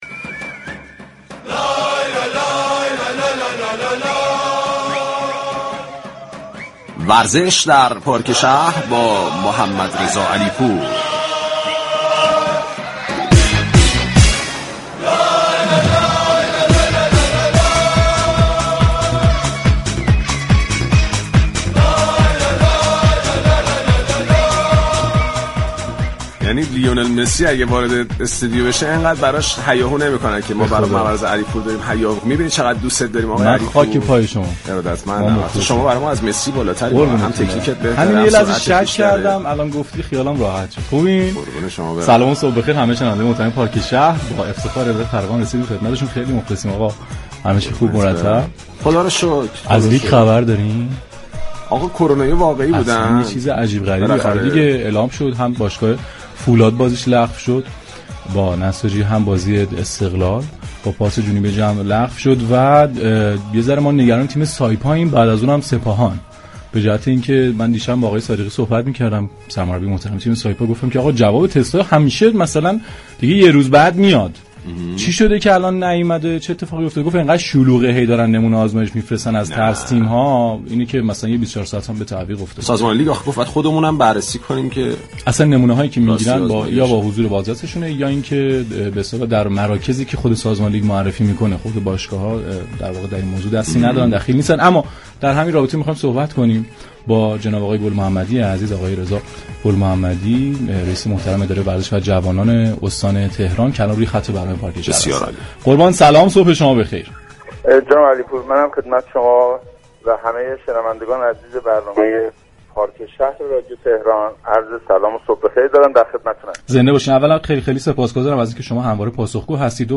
رضا گل محمدی در گفتگوی با خبرنگار پارك شهر رادیو تهران، 16 تیرماه گفت: متاسفانه عدم رعایت موارد بهداشتی توسط كل جامعه سبب شد تا شاهد برگشت موج دوم كرونا بوده و خانواده‌های زیادی را در سراسر كشورمان داغدار ببینیم.